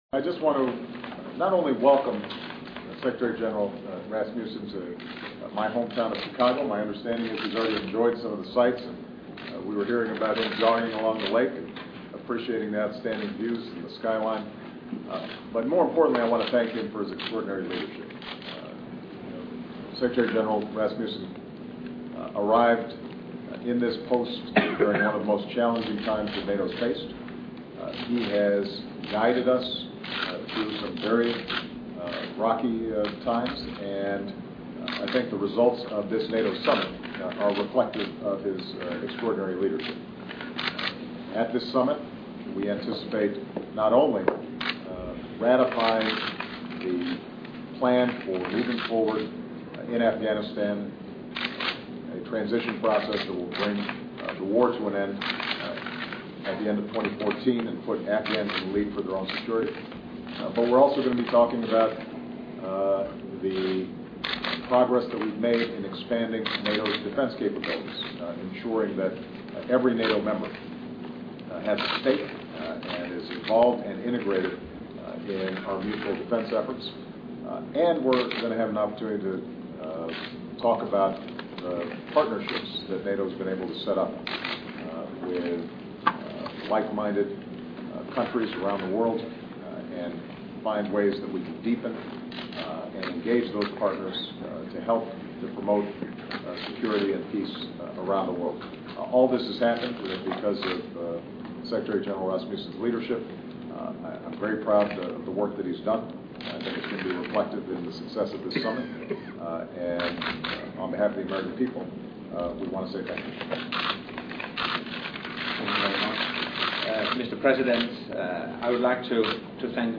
奥巴马总统每周电台演讲:总统致谢秘书长拉斯穆森 听力文件下载—在线英语听力室